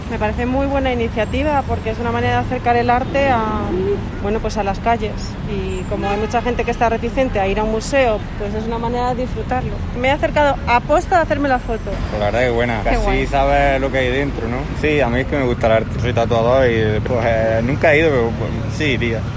Nos hemos ido hasta la Gran Vía y nos hemos situado frente a la recreación a tamaño real de una obra de Tiziano. Los viandantes nos han contado qué les parece esta idea: “Me parece muy buena iniciativa porque es una manera de acercar el arte a las calles.